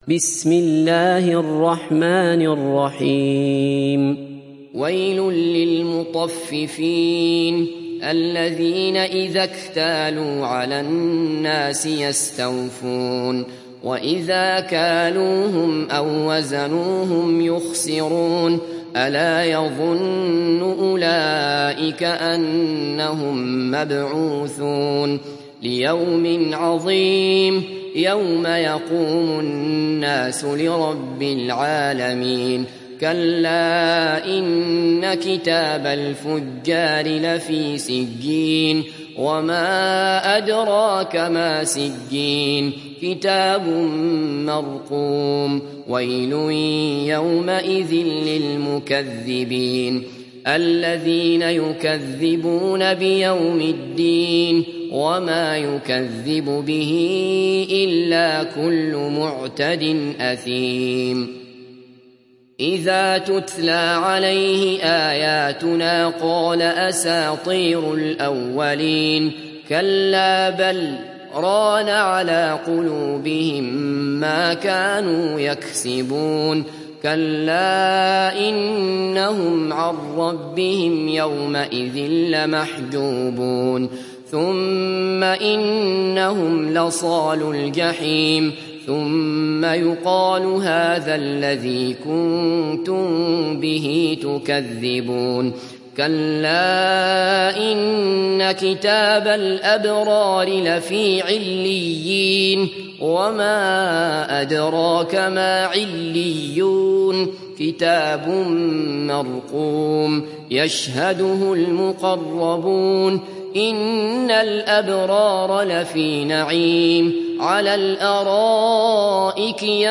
تحميل سورة المطففين mp3 بصوت عبد الله بصفر برواية حفص عن عاصم, تحميل استماع القرآن الكريم على الجوال mp3 كاملا بروابط مباشرة وسريعة